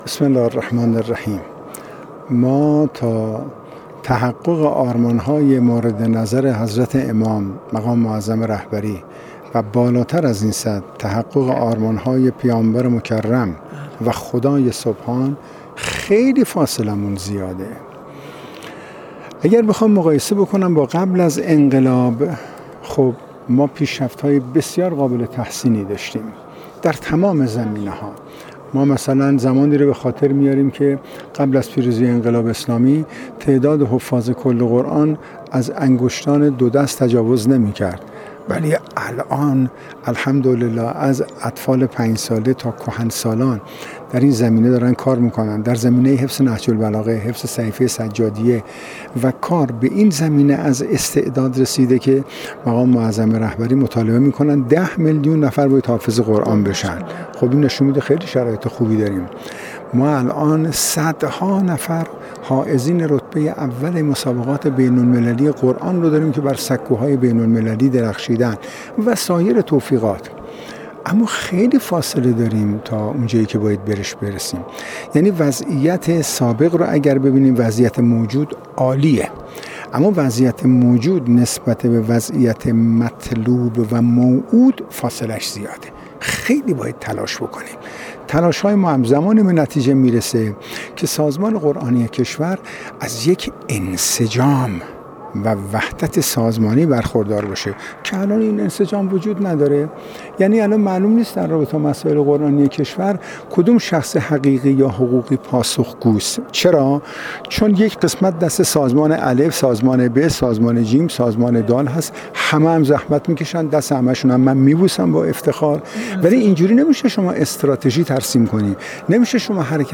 در گفت‌وگو با خبرنگار ایکنا